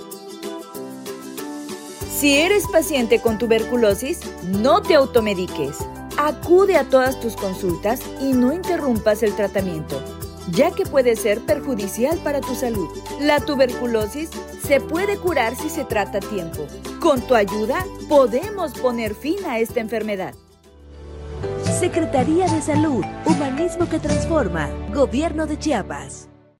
3.-Perifoneo-no-te-automediques.mp3